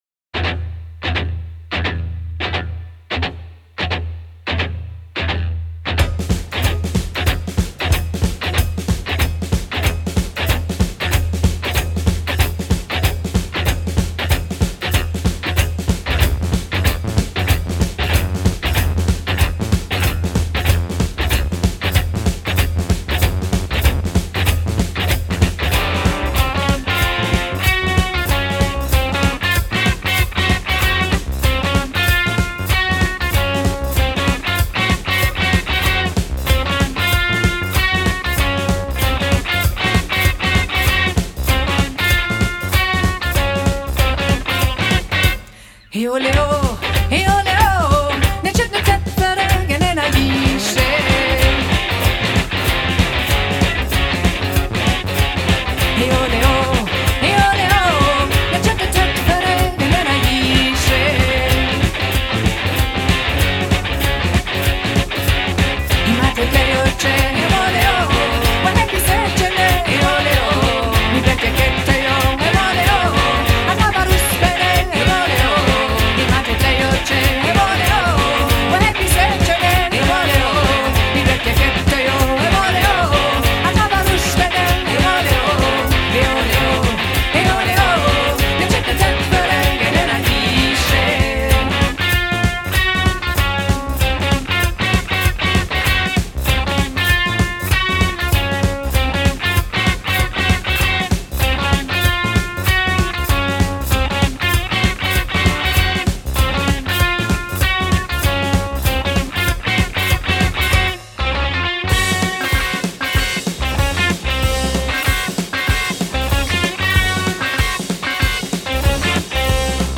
recorded in Chicago